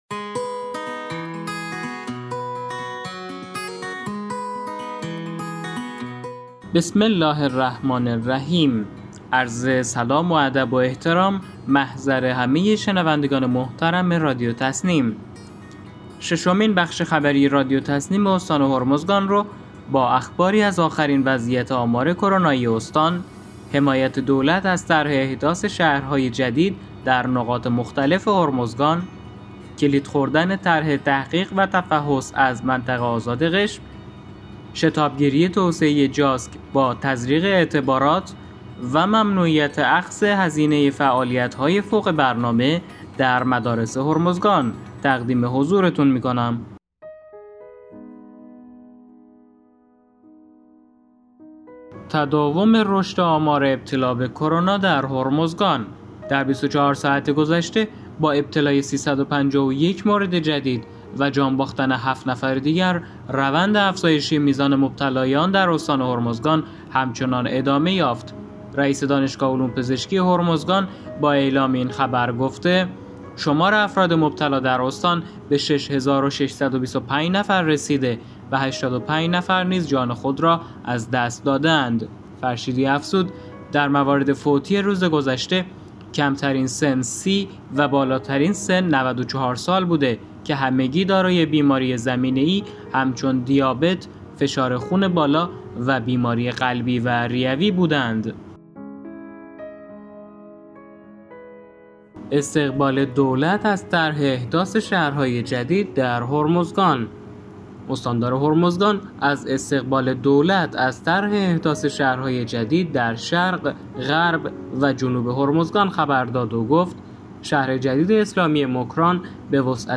به گزارش خبرگزاری تسنیم از بندرعباس، ششمین بخش خبری رادیو تسنیم استان هرمزگان با اخباری از آخرین وضعیت آمار کرونایی استان، حمایت دولت از طرح احداث شهرهای جدید نقاط مختلف هرمزگان، کلید خوردن طرح تحقیق و تفحص از منطقه آزاد قشم، شتاب گیری توسعه جاسک با تزریق اعتبارات و ممنوعیت اخذ هزینه فعالیت‌های فوق برنامه در مدارس هرمزگان منتشر شد.